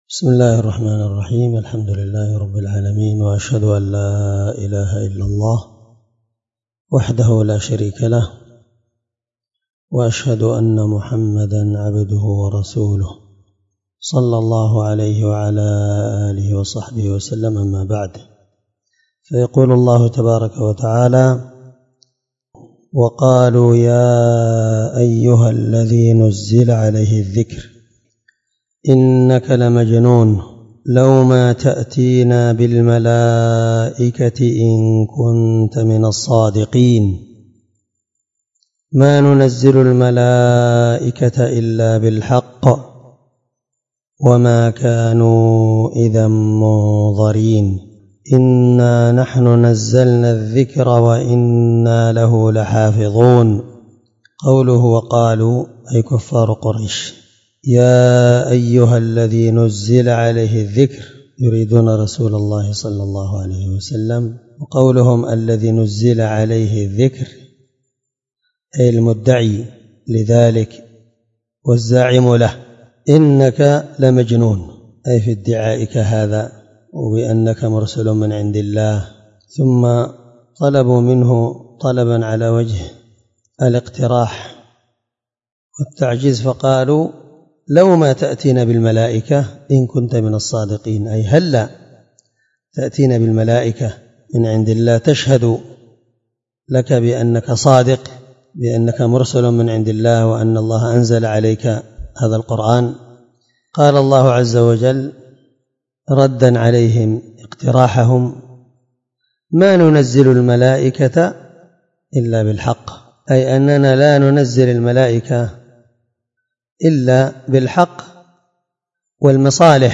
711الدرس 2 تفسير آية (6-9) من سورة الحجر من تفسير القرآن الكريم مع قراءة لتفسير السعدي